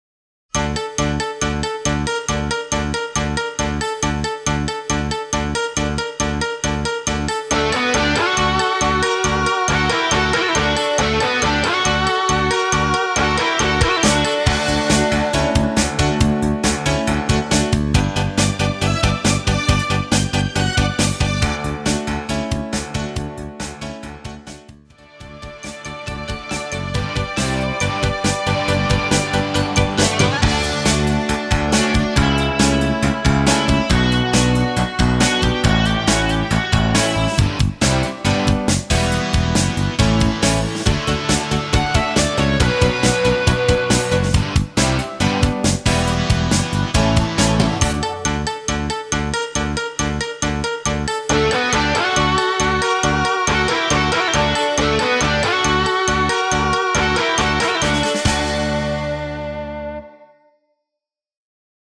엔딩이 페이드 아웃이라 엔딩을 만들어 놓았습니다.(미리듣기 참조) 키 D 가수
원곡의 보컬 목소리를 MR에 약하게 넣어서 제작한 MR이며